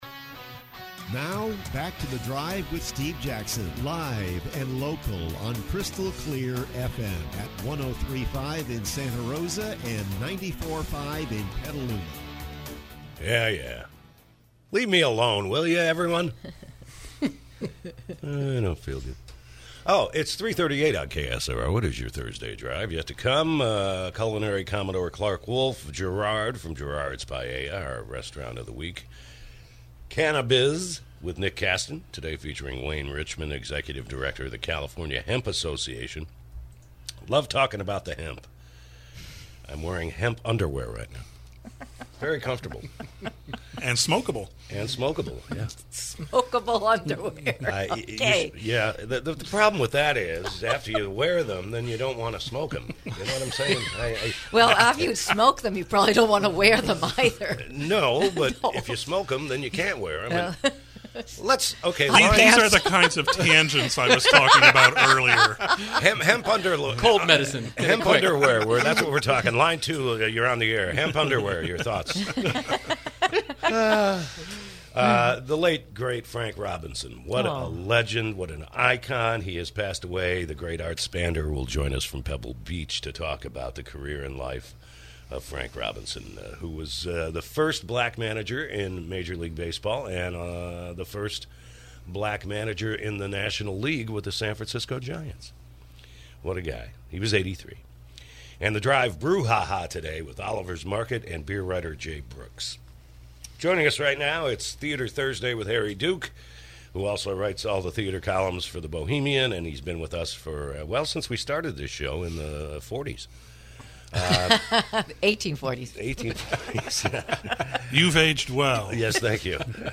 KSRO Interview: “Underneath the Lintel”